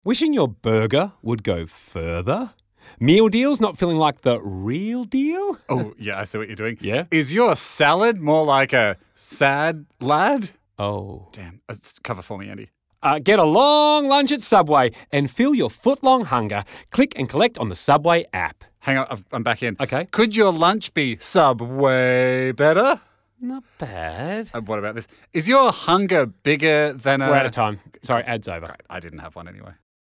Voiced by comedy duo, Hamish Blake and Andy Lee, the campaign pokes fun at the amusingly disproportionate size of the Footlong alongside competitor burgers.